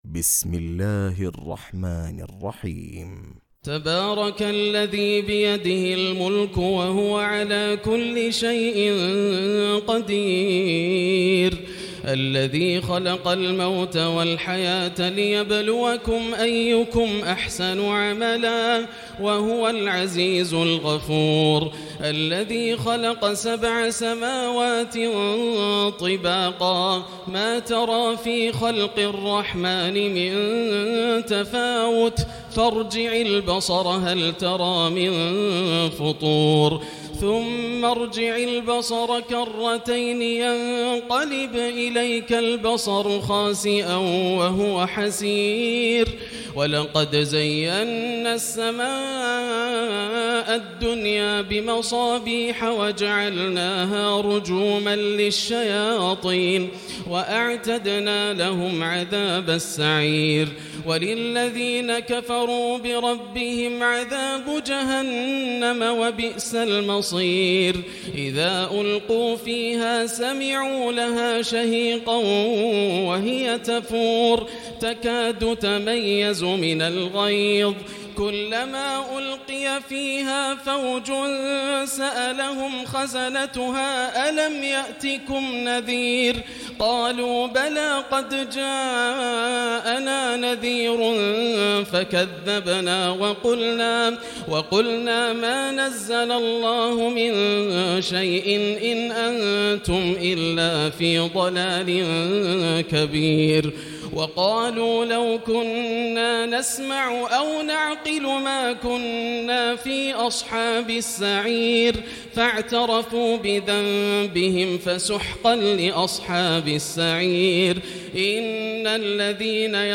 تراويح ليلة 28 رمضان 1439هـ من سورة الملك الى نوح Taraweeh 28 st night Ramadan 1439H from Surah Al-Mulk to Nooh > تراويح الحرم المكي عام 1439 🕋 > التراويح - تلاوات الحرمين